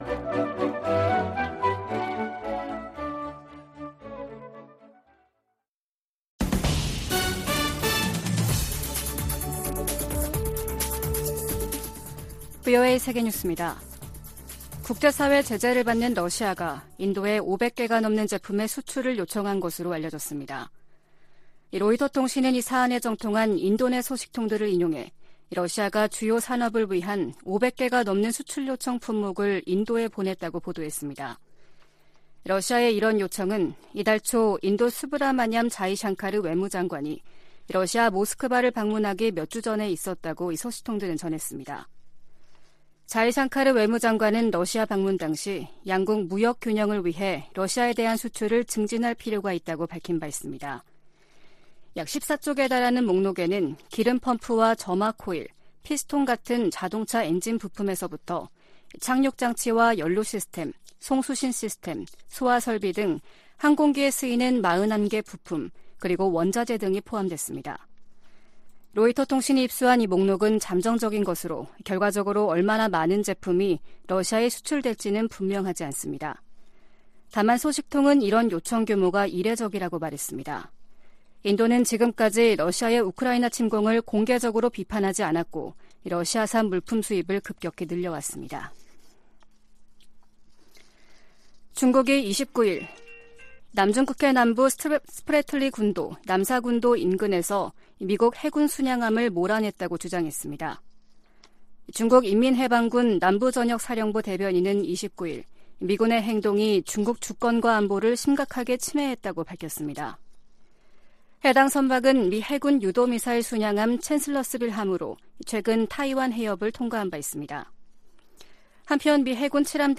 VOA 한국어 아침 뉴스 프로그램 '워싱턴 뉴스 광장' 2022년 11월 30일 방송입니다. 김정은 북한 국무위원장은 최근의 대륙간탄도미사일 시험발사를 현지 지도하며 대륙간 탄도미사일 부대를 처음 언급했습니다. 북한의 장거리 탄도미사일 발사가 미국 본토에 대한 위협이 되지 않으나 북한이 역내에 제기하는 위협을 우려한다고 백악관 고위 관리가 밝혔습니다.